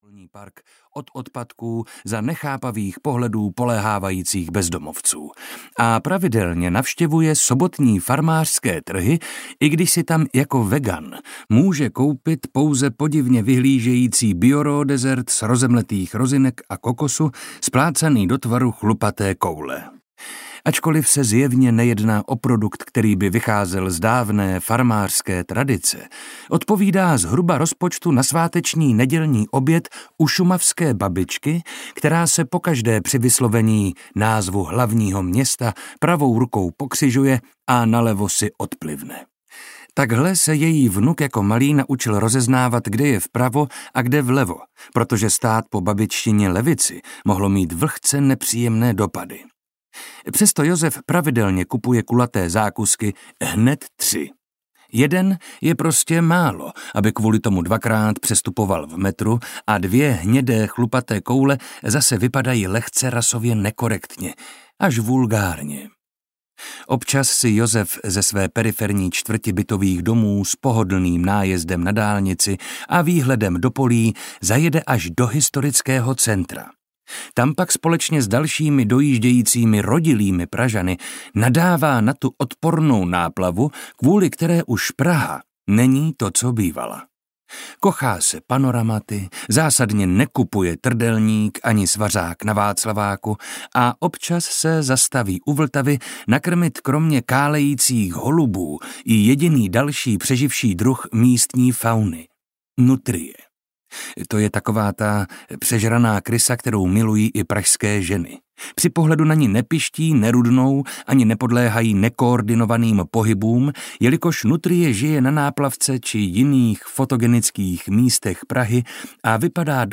Muž z Brna audiokniha
Ukázka z knihy
• InterpretMarek Holý